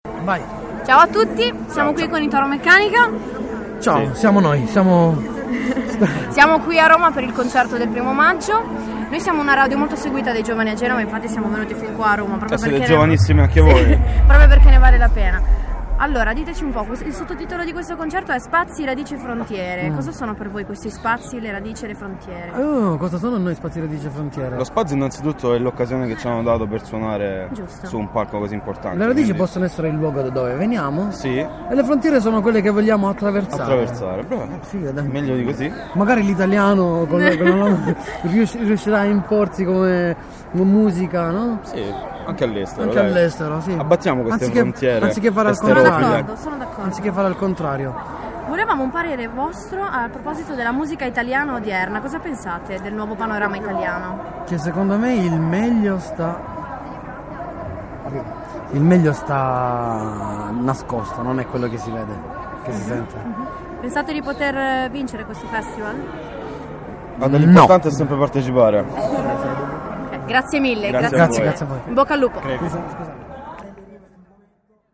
play_circle_filled Intervista a Toromeccanica (concerto del I° Maggio - Roma) Radioweb C.A.G. di Rapallo Gruppo Rock intervista del 01/05/2013 Intervista al gruppo rock italiano "Toromeccanica" presente al concerto del I° maggio a Roma. Un gruppo giovane proveniente dal sud italia (Puglia) con un album all'attivo.